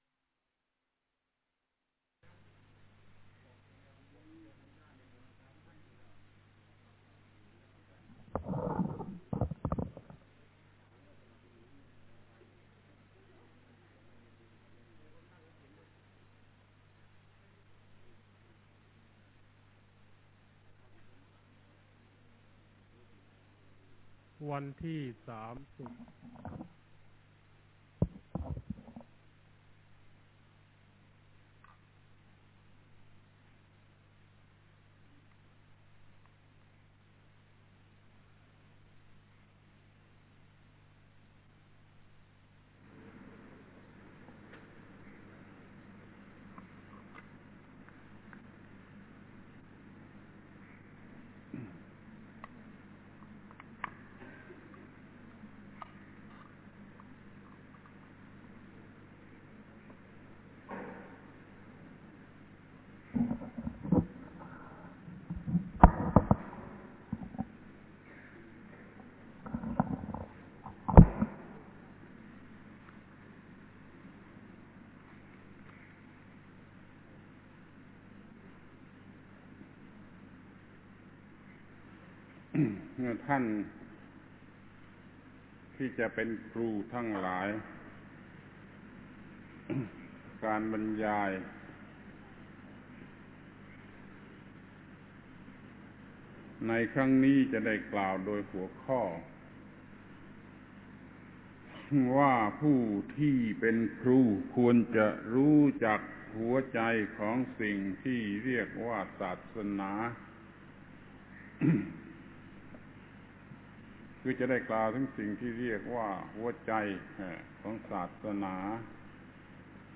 Title อบรมนักเรียนฝึกหัดครูสงขลา รุ่น 2 ปี 2515 ครั้ง 5 ผู้ที่เป็นครูควรจะรู้สิ่งที่เป็นหัวใจพุทธศาสนา เสียง 1592 อบรมนักเรียนฝึกหัดครูสงขลา รุ่น 2 ปี 2515 ครั้ง 5 ผู้ที่เป็นครูควรจะรู้สิ่งที่เป็นหัวใจพุทธศาสนา /buddhadasa/2-2515-5.html Click to subscribe Share Tweet Email Share Share